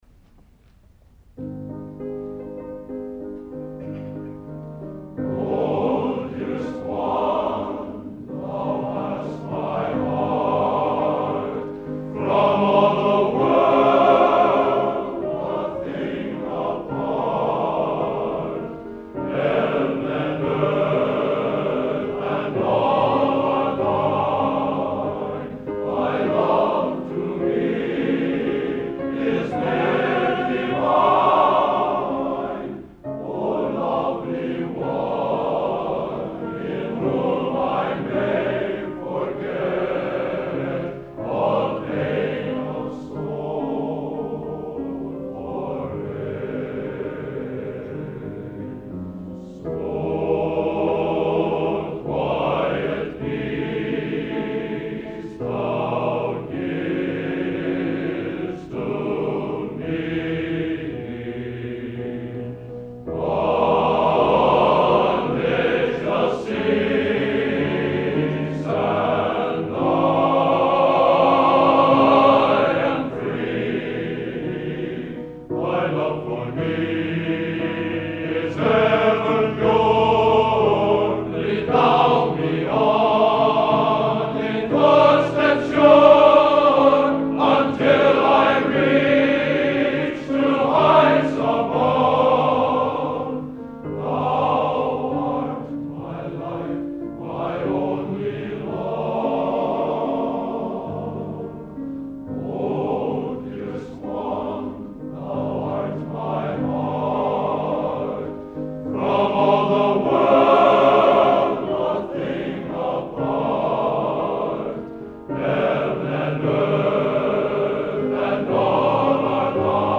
Collection: End of Season, 1964
Location: West Lafayette, Indiana
Genre: | Type: End of Season